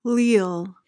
PRONUNCIATION: (leel) MEANING: adjective: Loyal; honest; true.